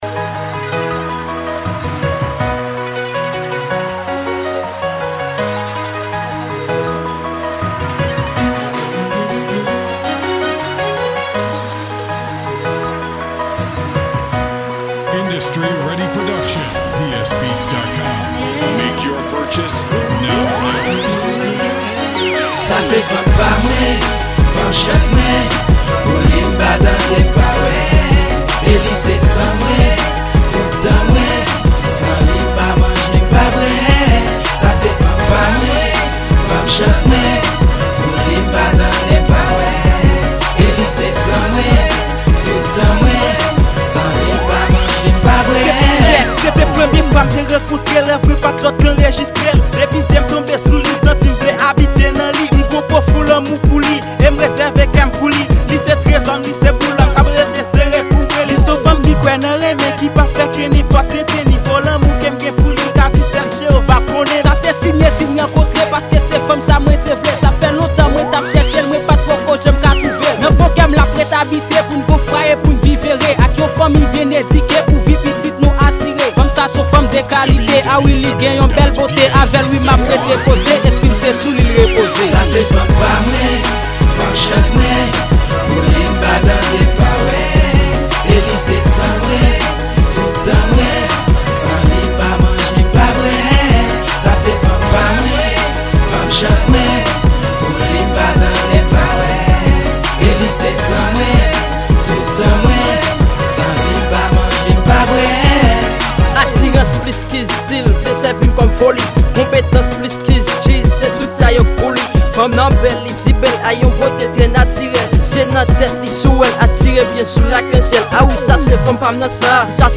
Genre: RAP & RNB.